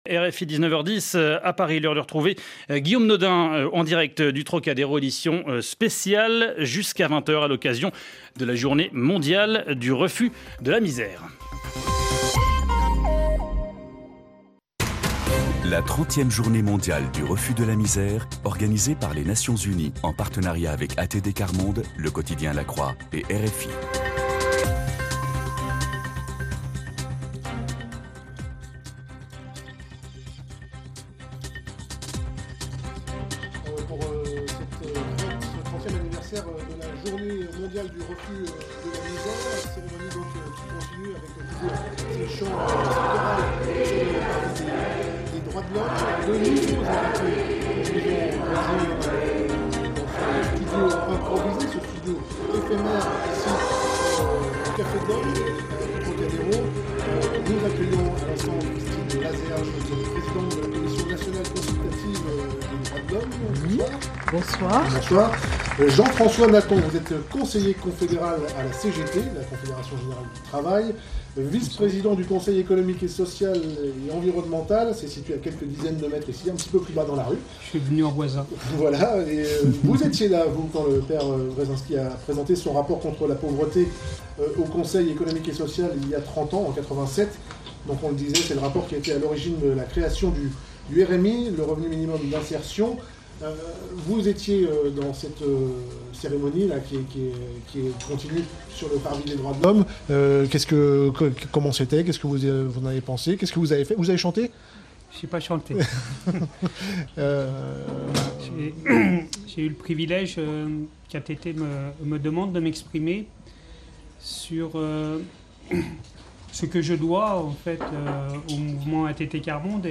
RFI a clôturé le partenariat avec ATD Quart Monde par une édition spéciale d’information et de débats, le 17 octobre.
Plusieurs intervenants ont parlé de la journée mondiale du refus de la misère et comment, depuis 30 ans, elle a contribué à la transformation du regard ou de la pratique professionnelle et associative :